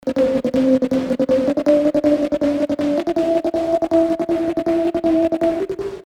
描述：为一首Hip Hop歌曲提供背景技术声音，以建立起一种新的氛围。
Tag: 80 bpm Hip Hop Loops Synth Loops 1.01 MB wav Key : Unknown